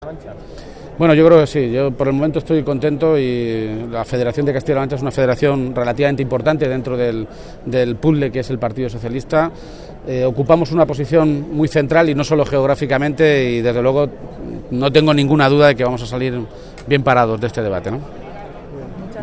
García-Page se pronunciaba de esta manera esta mañana, en Madrid, a su llegada al Congreso Extraordinario en el que el PSOE de toda España ratificará a Pedro Sánchez como nuevo secretario general y elegirá a su nueva dirección.